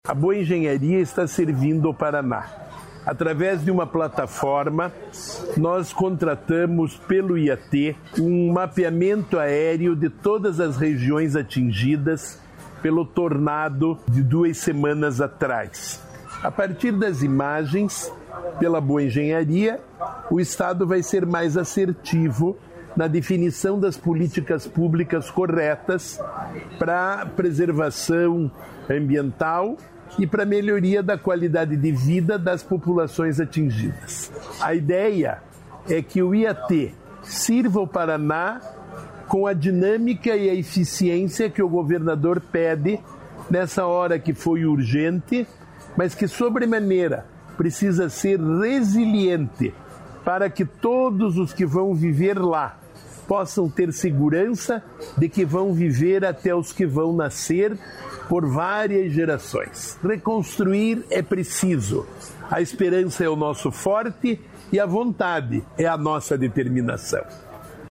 Sonora do secretário estadual do Desenvolvimento Sustentável, Rafael Greca, sobre o mapeamento aéreo de Rio Bonito do Iguaçu